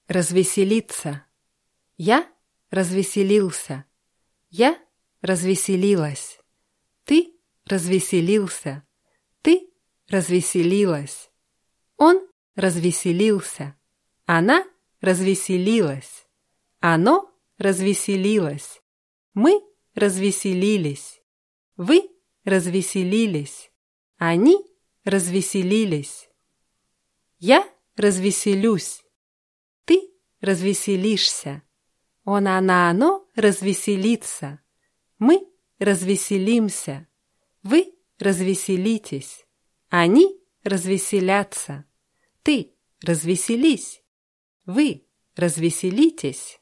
развеселиться [razwʲißʲilʲítsa]